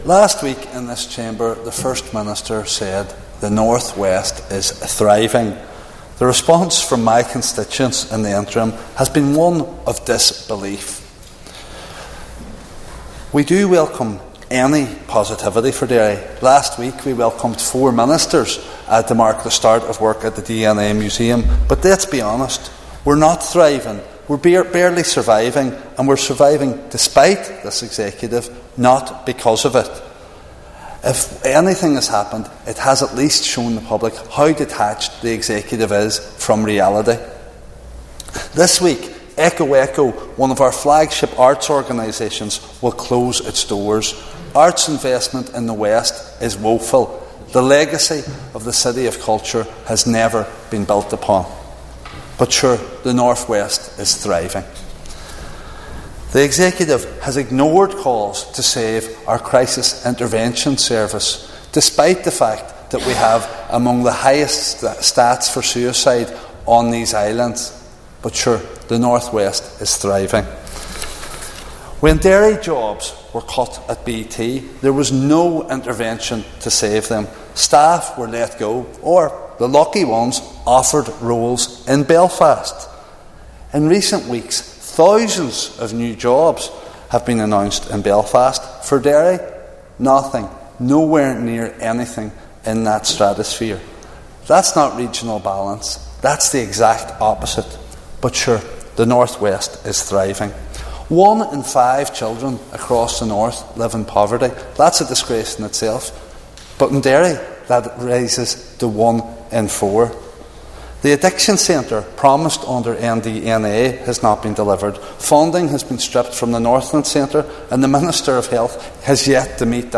In the chamber this week, Foyle MLA Mark Durkan said Derry is experiencing record homelessness and lack of opportunity, at a tome when services are being scaled back, including the city’s Crisis Intervention Service.